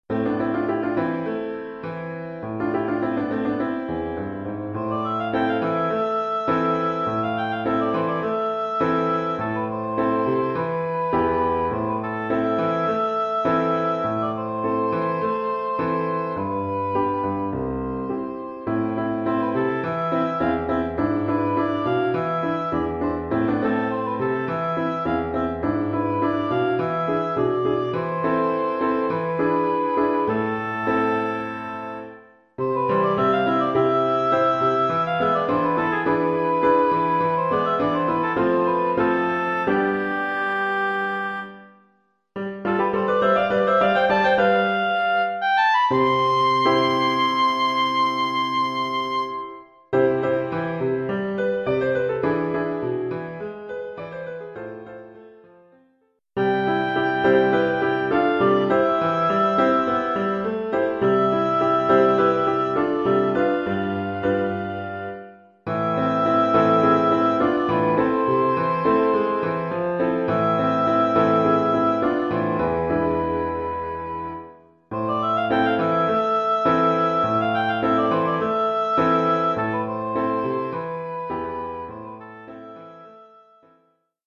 Oeuvre pour hautbois et piano.